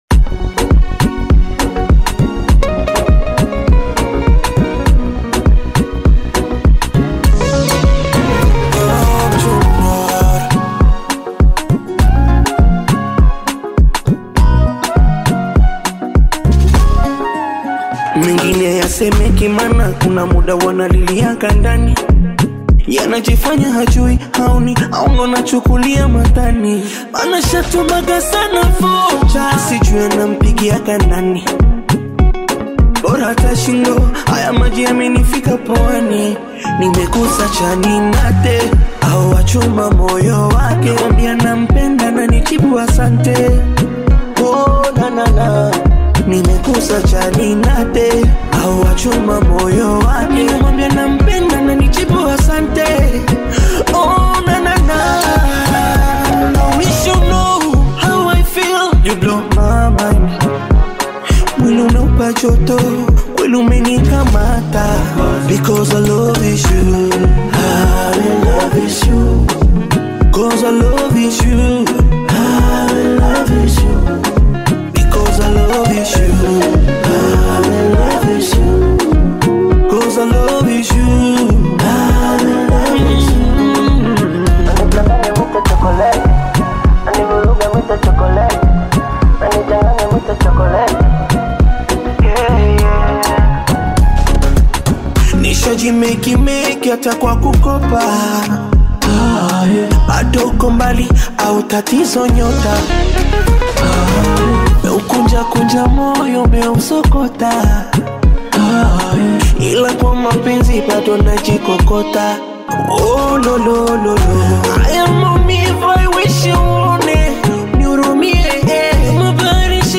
Love song
African Music